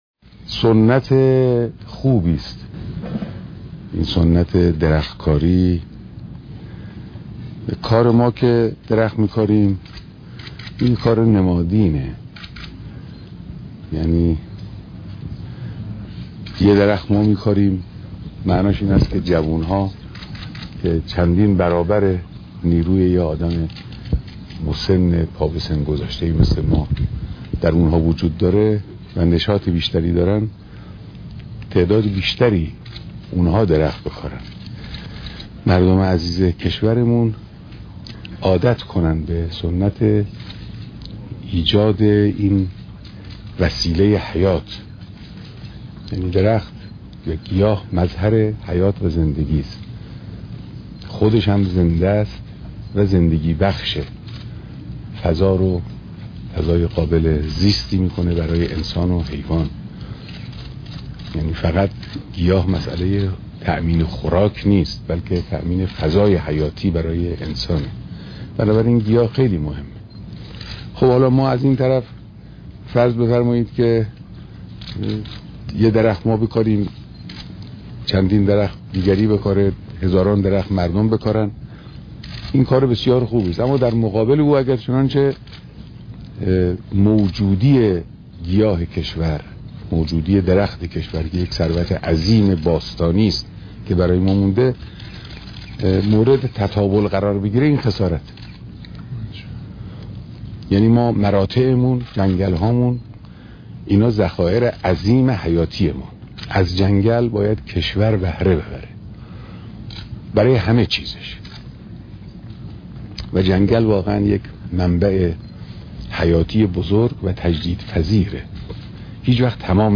بيانات در مراسم كاشت نهال در هفته منابع طبیعی
سخنرانی